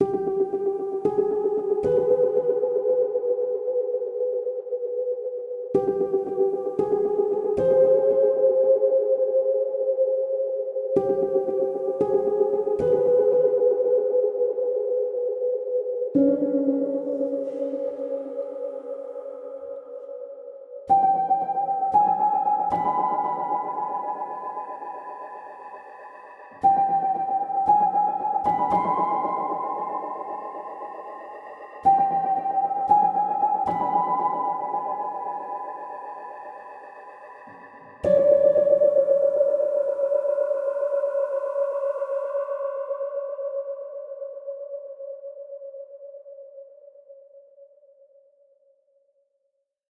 dark piano horror tension